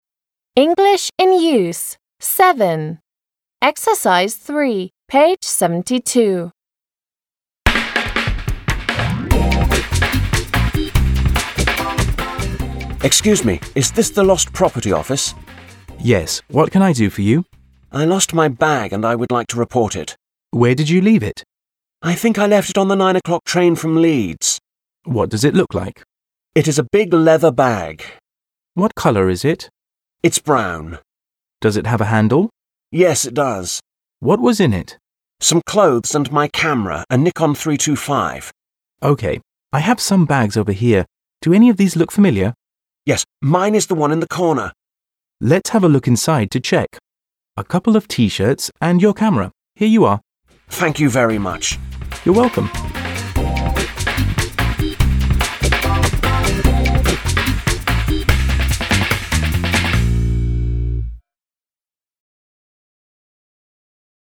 They are from a dialogue at the lost property desk.